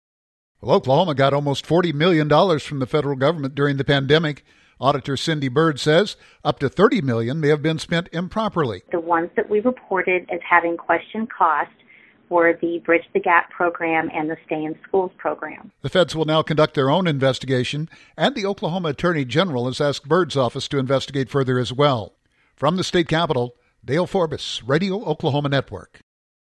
Auditor Cindy Byrd said up to 30 million dollars may have been spent improperly.